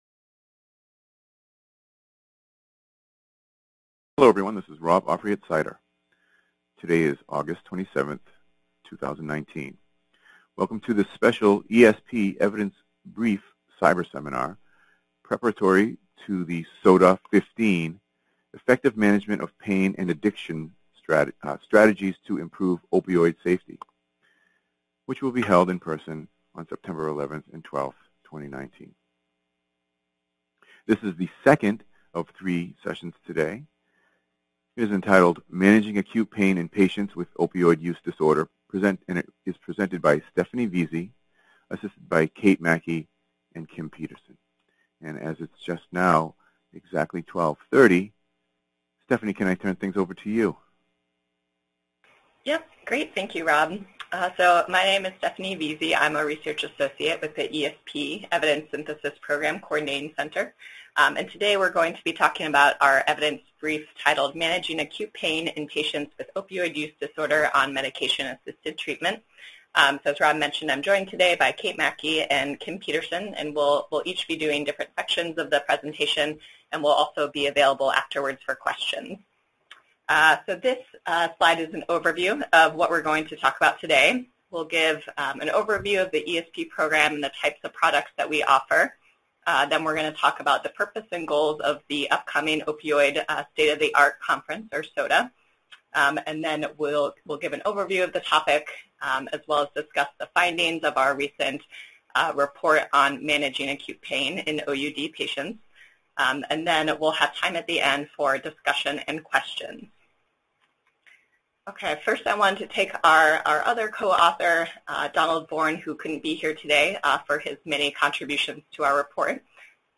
Description: WG3 - Managing Acute Pain in Patients with Opioid Use Disorder. 2nd of three ESP Evidence Brief presentations on August 27 2019, prior to SOTA15: Effective Management of Pain and Addiction: Strategies to Improve Opioid Safety